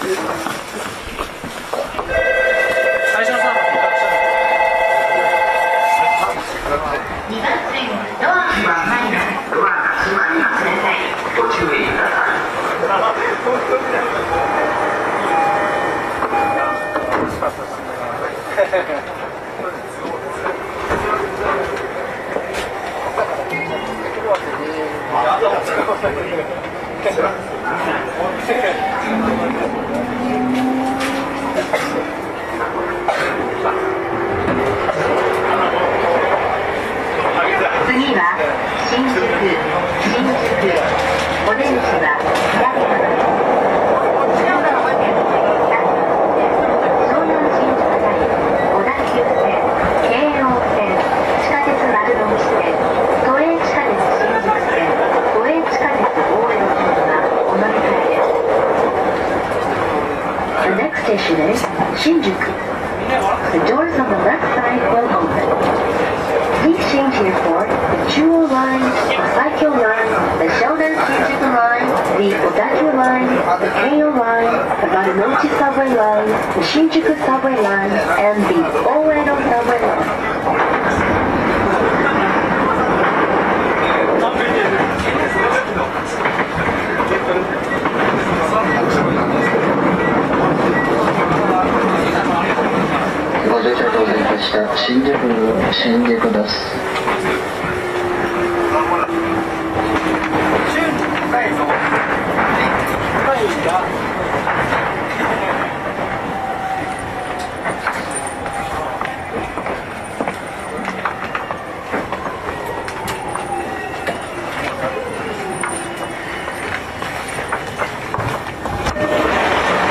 走行音
YM02 E231系 新大久保-新宿 2:23 8/10 --